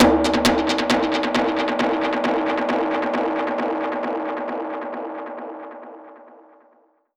Index of /musicradar/dub-percussion-samples/134bpm
DPFX_PercHit_D_134-02.wav